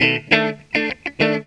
GTR 54 EM.wav